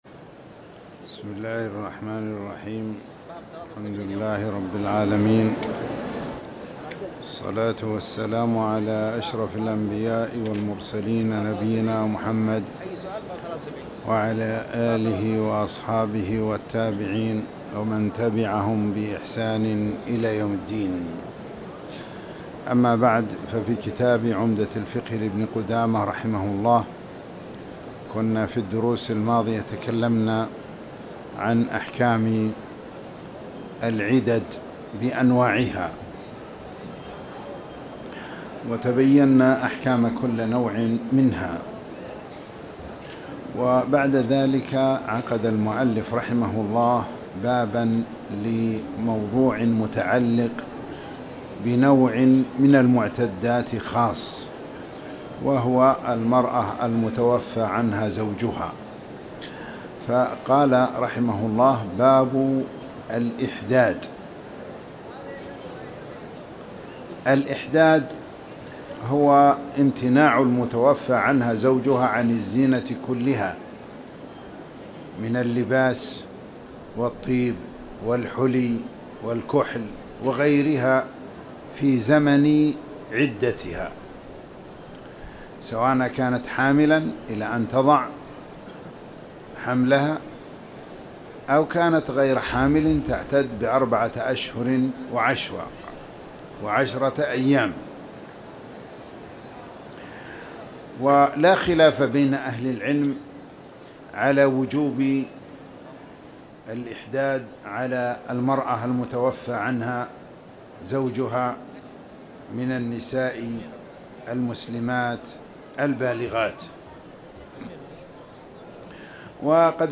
تاريخ النشر ٢٣ جمادى الآخرة ١٤٣٨ هـ المكان: المسجد الحرام الشيخ